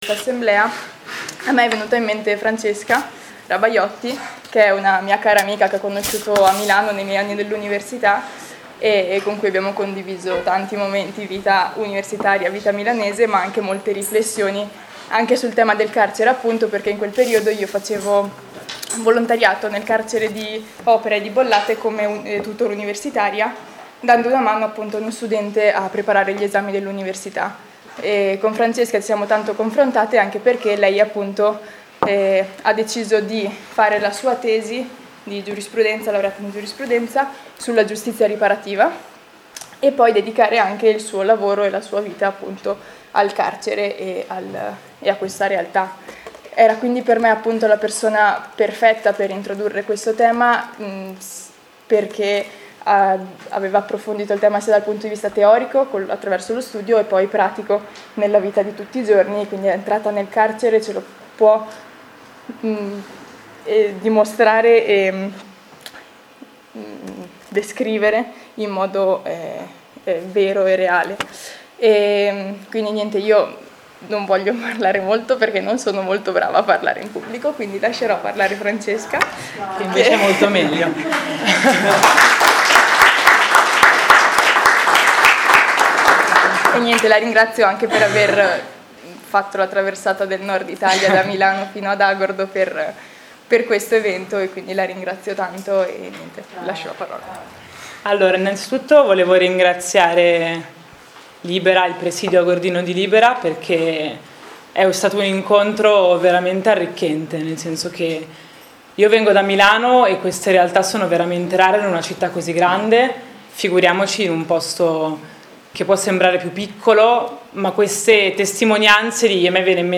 ALL’ASSEMBLEA DI LIBERA AGORDINA